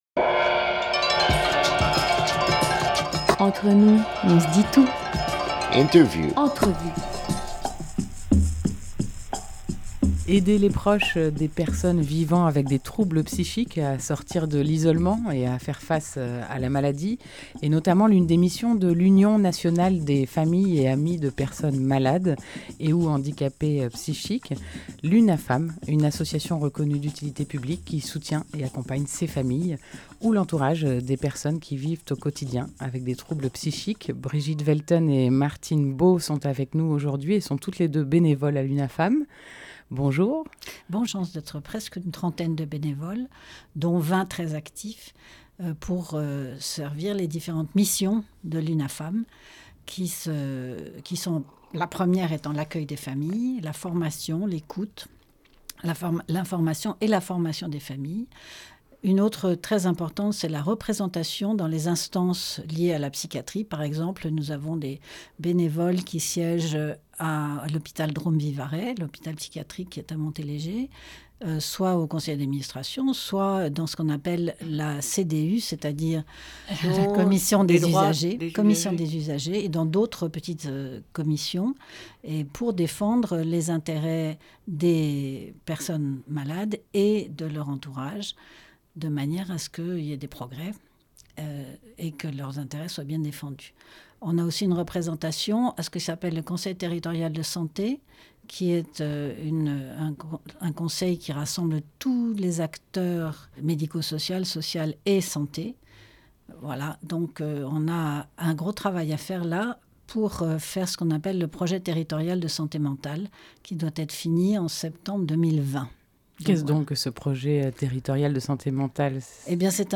10 décembre 2018 9:57 | Interview